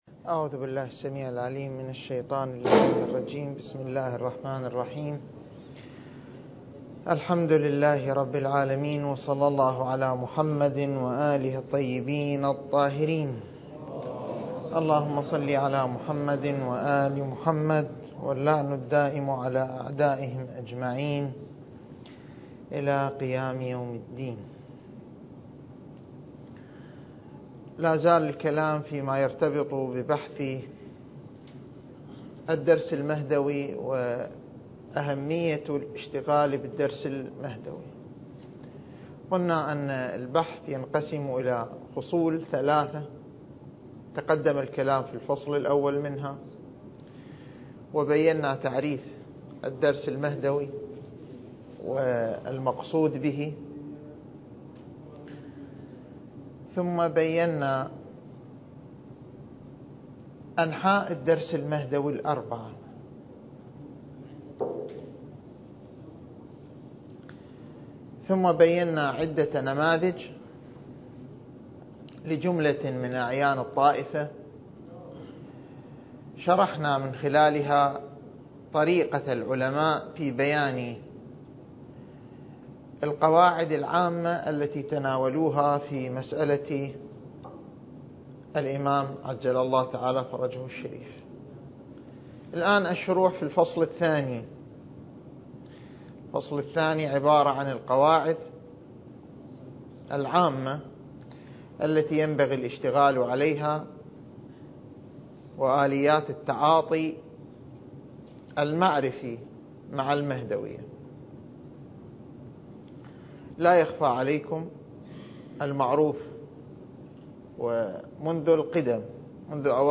(المحاضرة الثامنة والعشرون)
المكان: النجف الأشرف